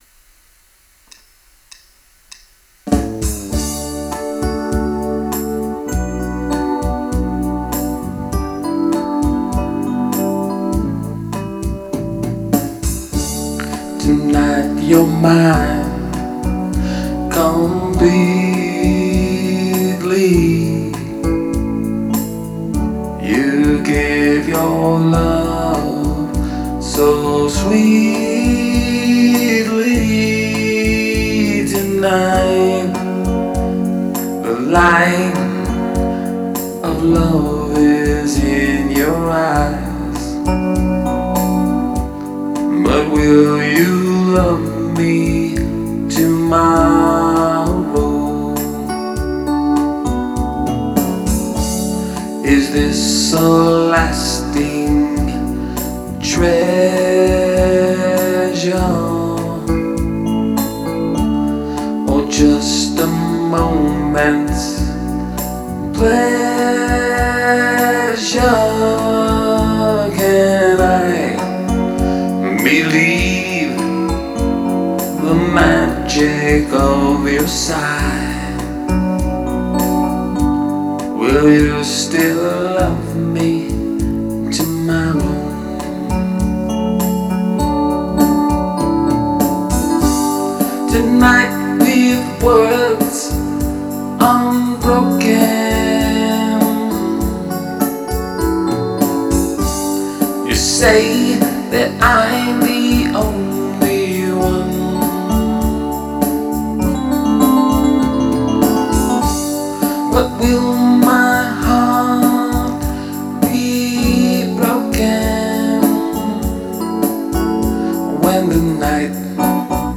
LIVE MUSIC: